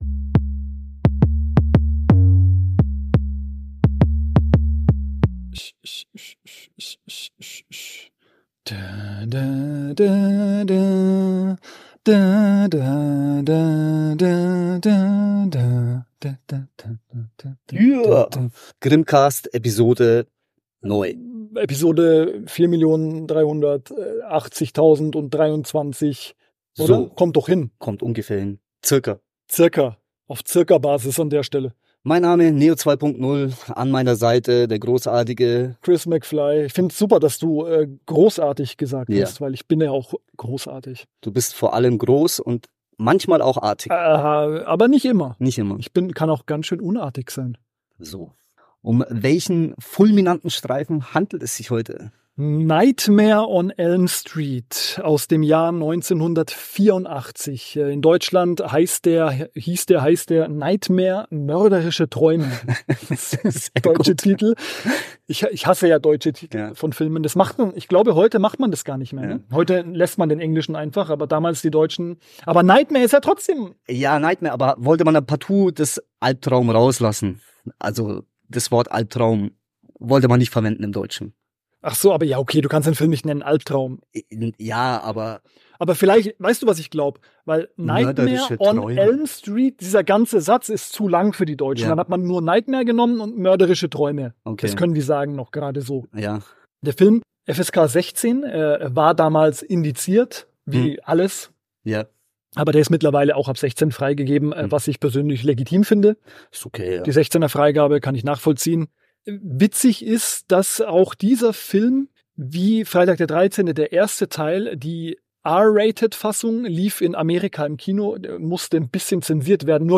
Darüber, ob Nightmare mit den Freitag der 13. Filmen mithalten kann, diskutieren die grimmigen Brüder in dieser jetzt schon kultigen Episode des Grimmcast.